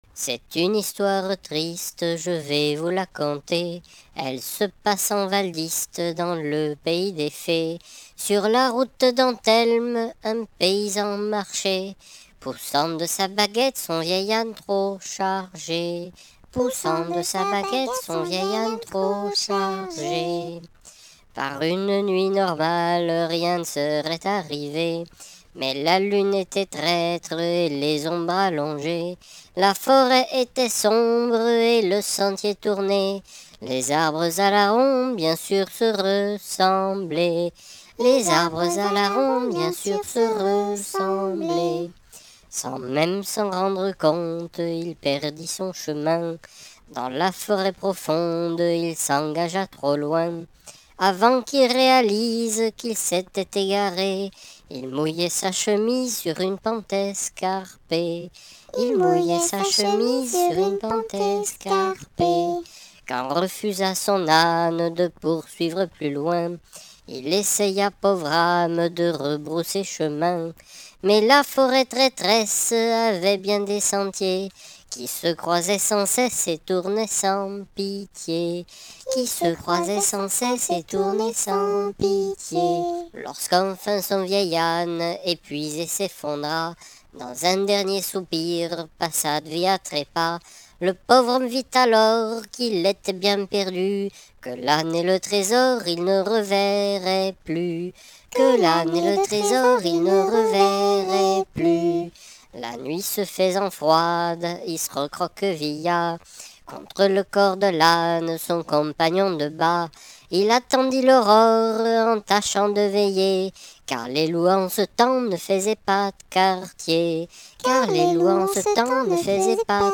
chanson populaire